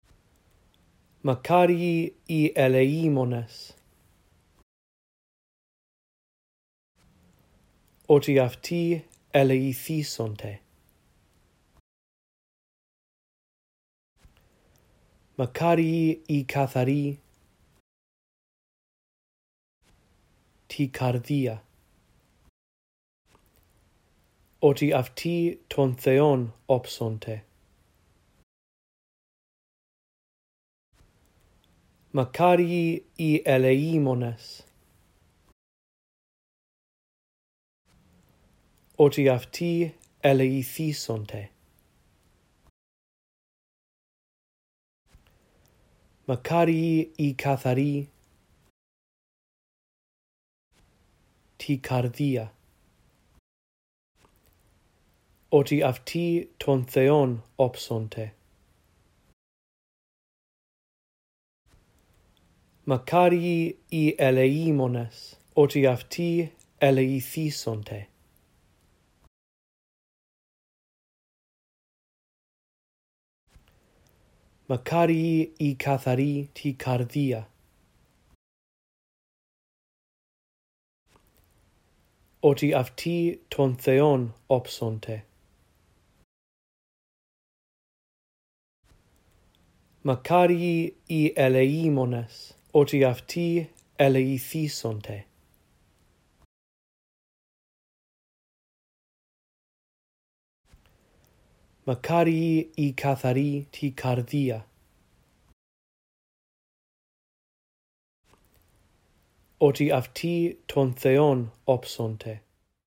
4. Listen to verses 7-8 and repeat during the pauses. Repeat until you have this text memorized.